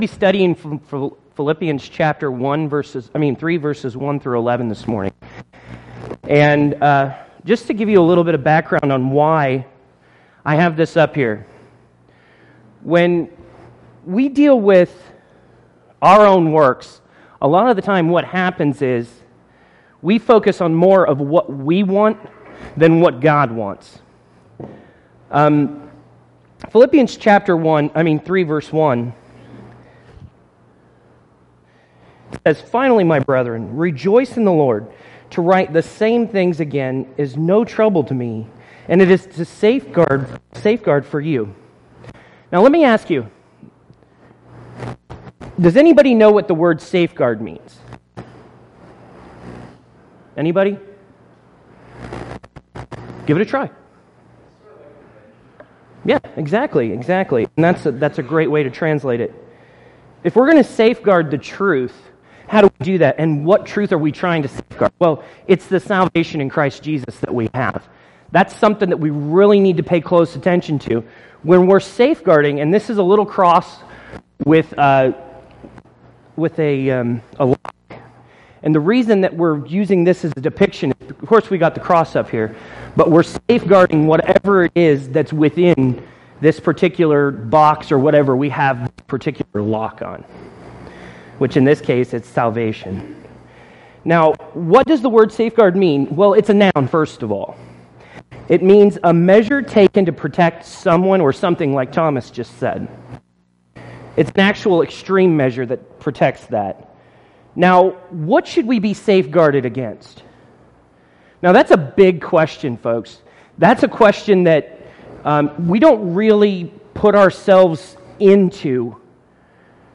Men At Work? (Bible Study)